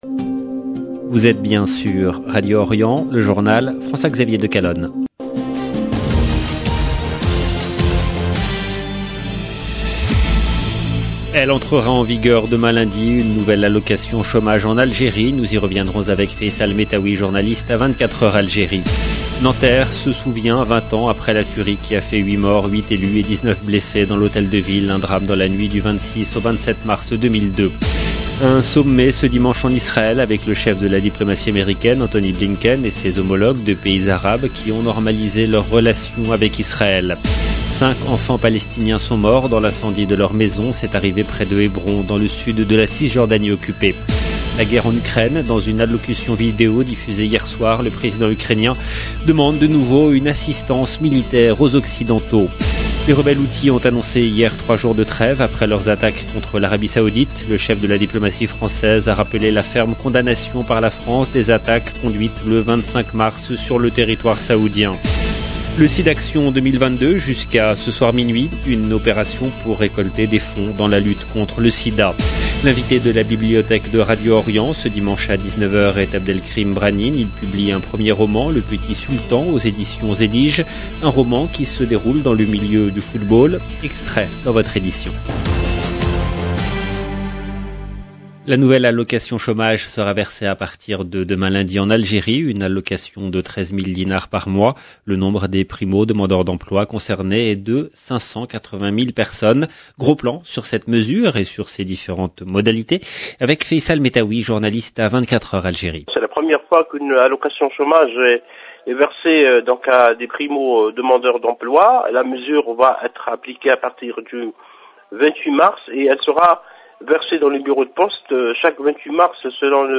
LE JOURNAL DU SOIR EN LANGUE FRANCAISE DU 27/3/2022